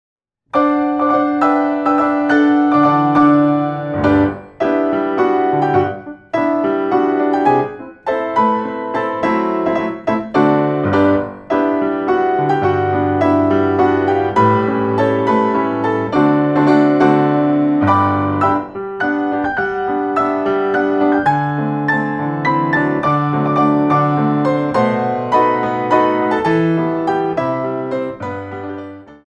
4 bar intro 3/4